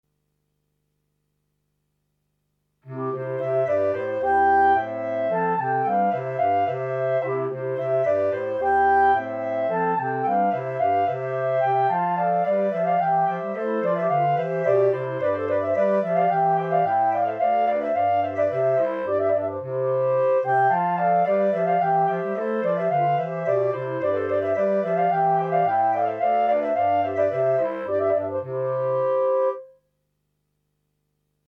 Clarinet Quartet
Instrumentation: 3 Clarinet, Bass Clarinet